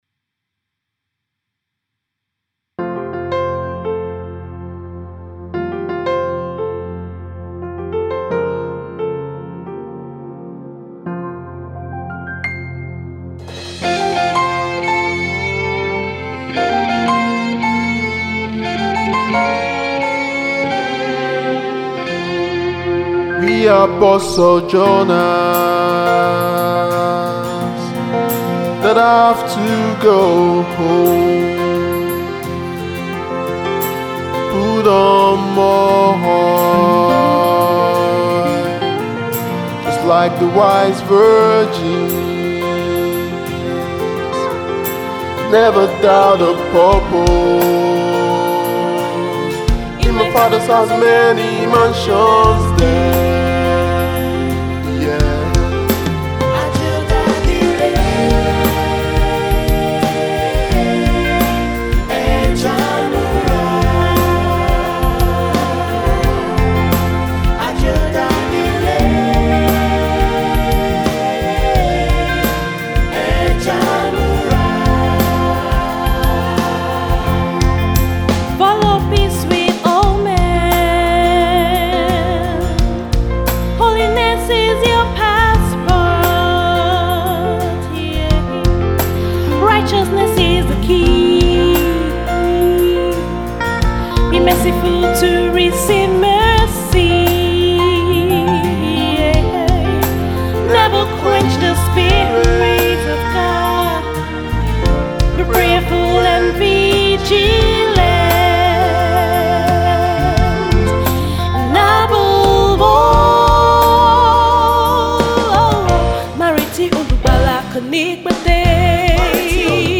a group of young Christians